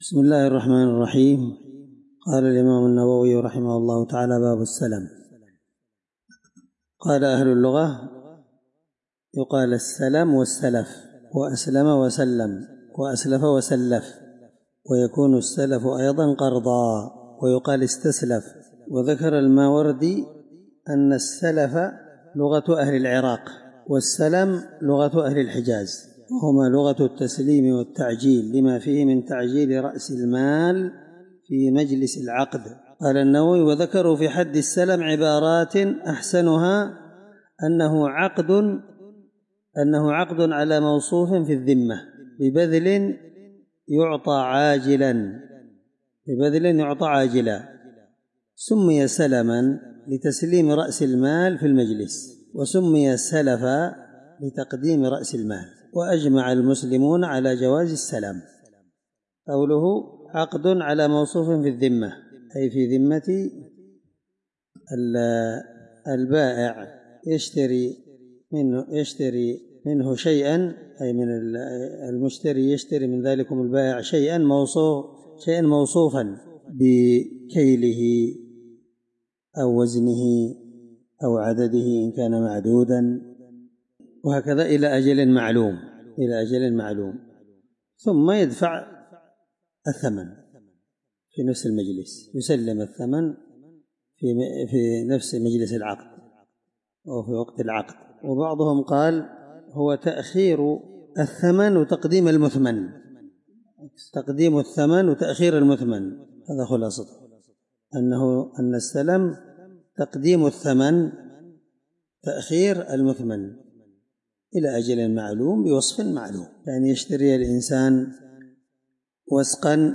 الدرس30من شرح كتاب المساقاة حديث رقم(1604) من صحيح مسلم